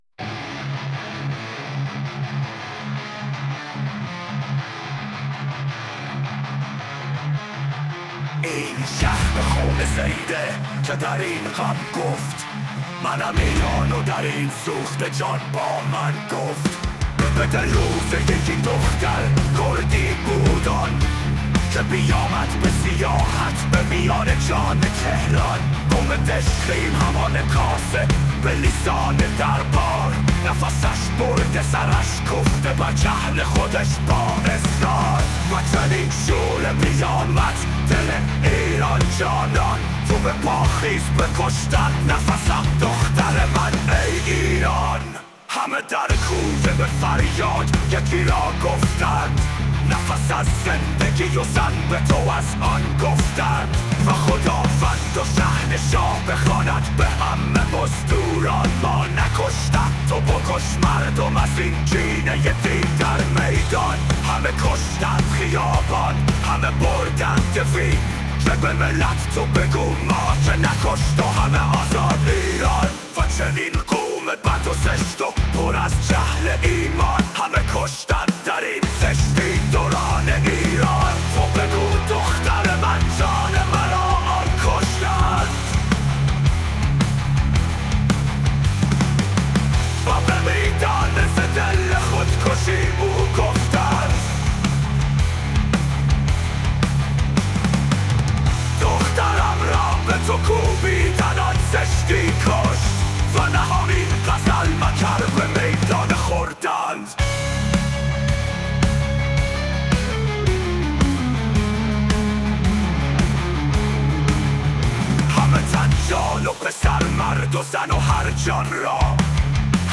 کتاب جان‌گرا؛ شعر صوتی؛ جلاد پلید: واکاوی استبداد و رهایی زن در ایران